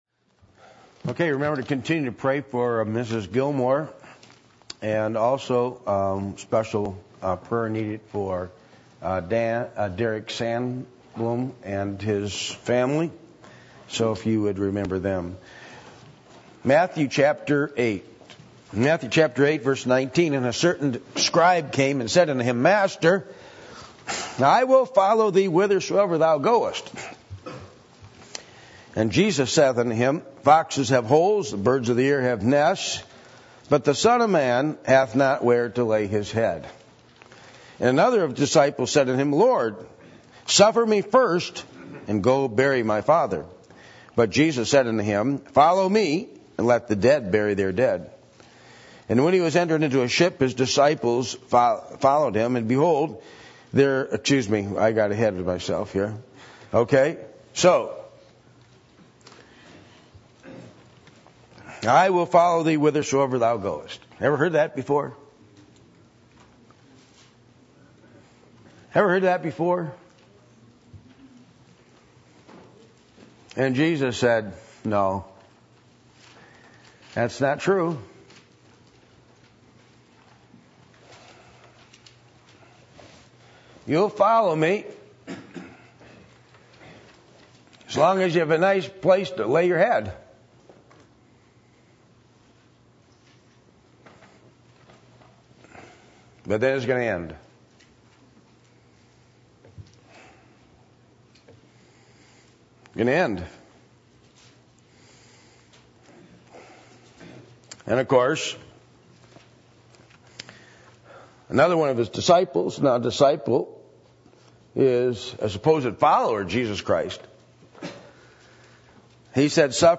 Passage: Matthew 8:19-22 Service Type: Midweek Meeting %todo_render% « A Biblical Look At Clothing